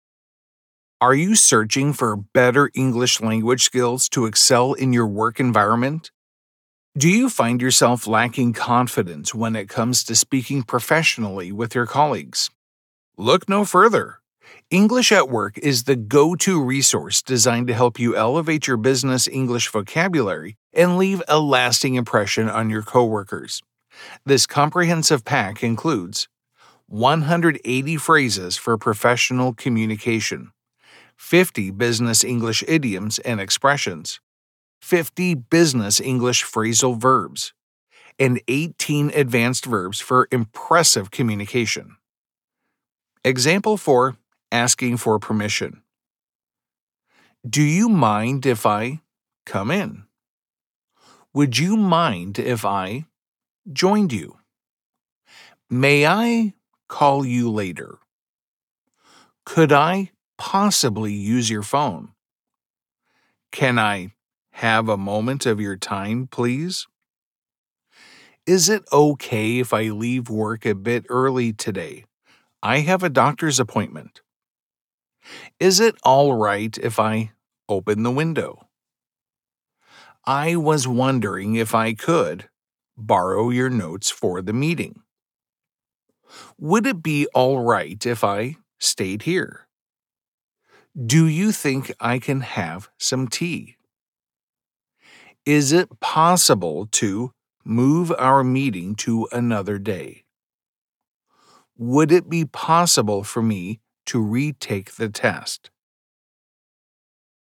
✅10 Audiobooks: Reinforce your pronunciation and listening comprehension anywhere, narrated by a professional (not AI)